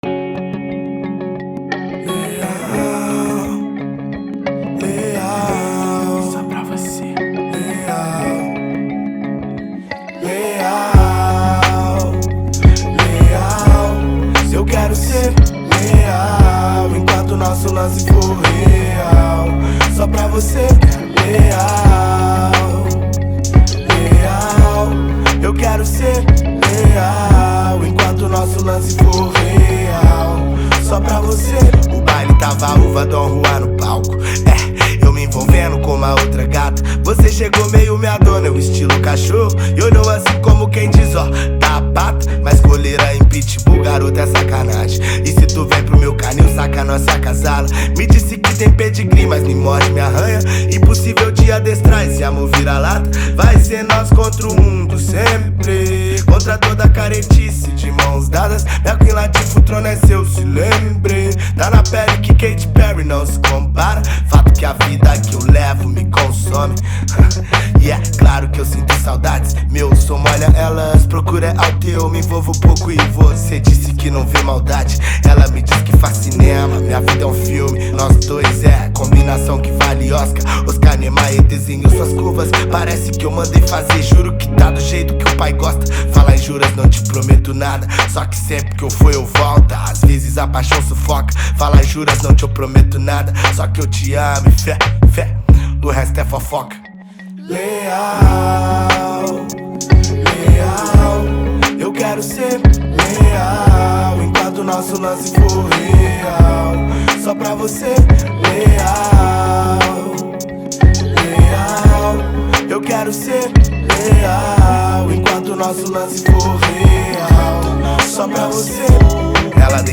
2025-02-08 01:28:13 Gênero: Rap Views